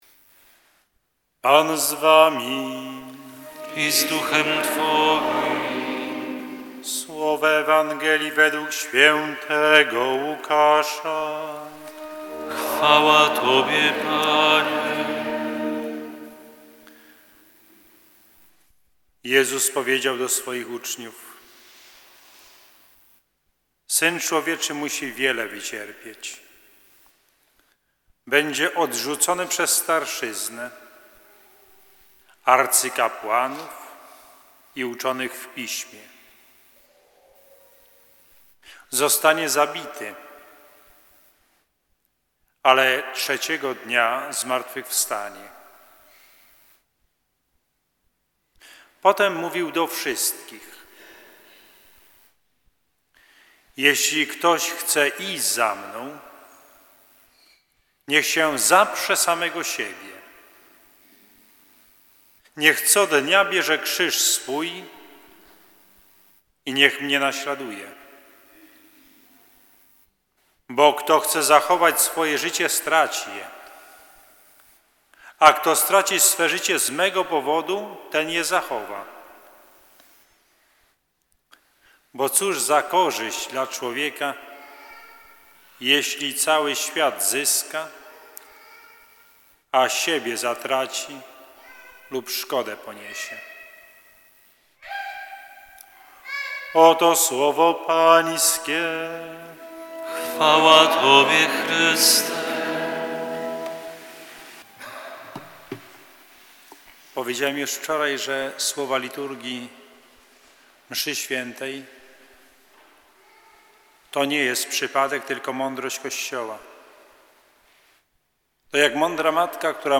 Rekolekcje raz jeszcze | Parafia p.w. św. Antoniego Padewskiego w Redzie
Rekolekcje raz jeszcze